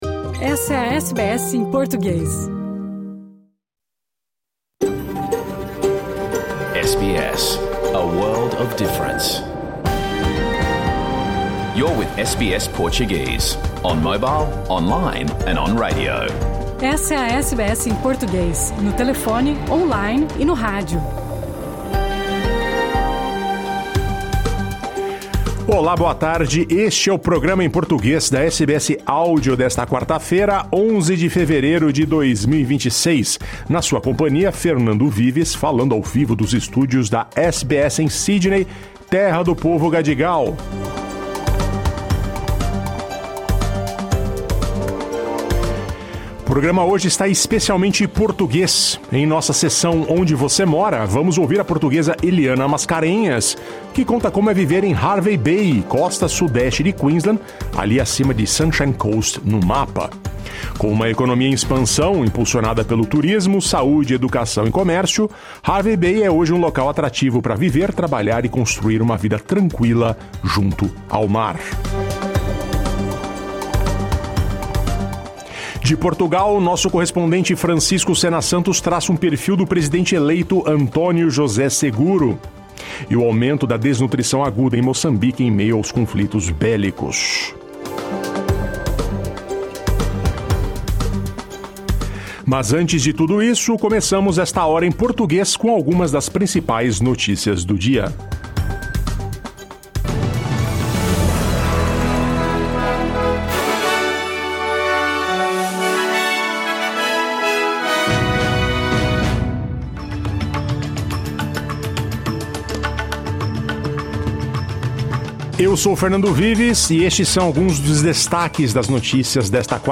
O programa em português que foi ao ar ao vivo pela SBS 2 em toda a Austrália: as notícias do dia.